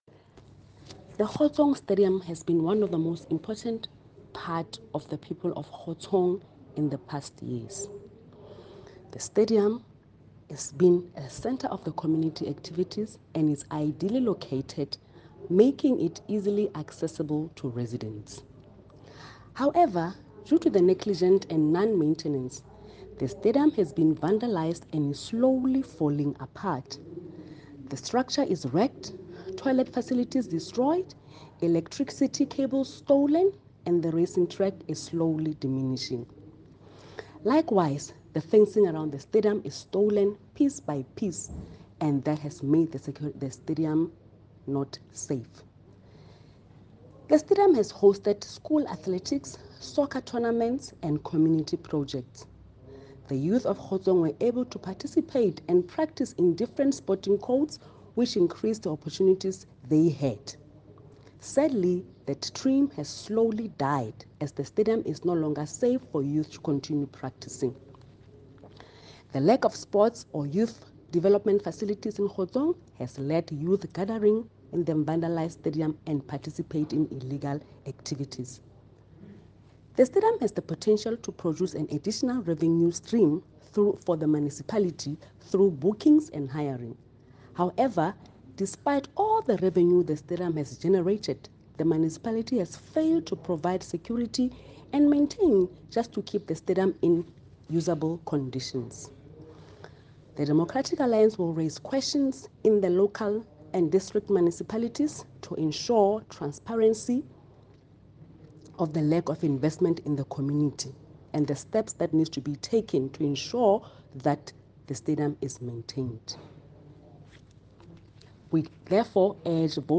Sesotho soundbites by Cllr Mahalia Kose.